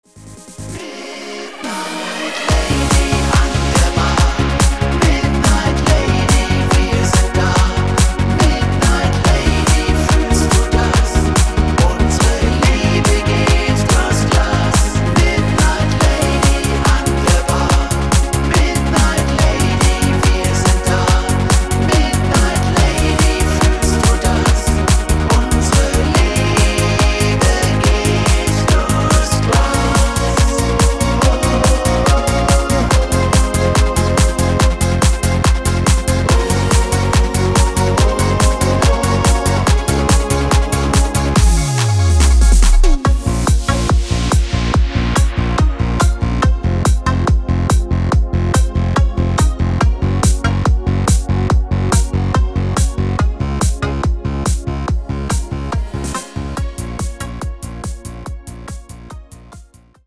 Rhythmus  8 Beat